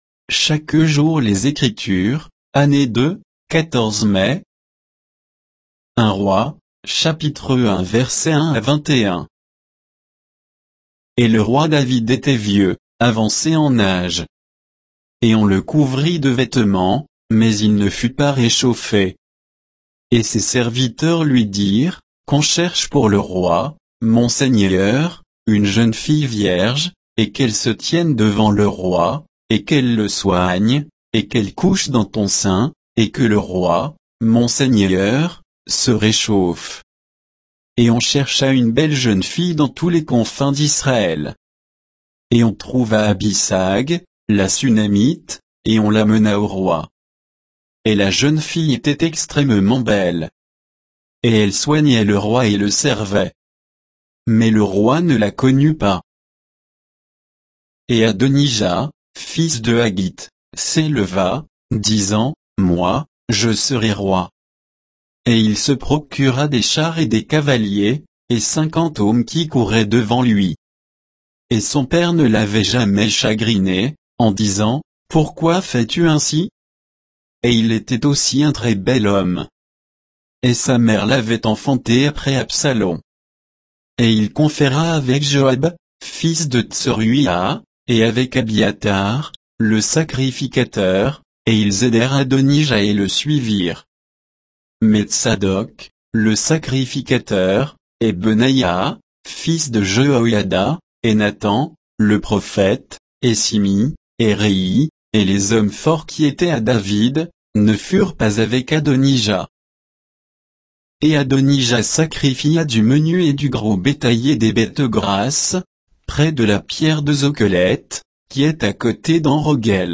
Méditation quoditienne de Chaque jour les Écritures sur 1 Rois 1, 1 à 21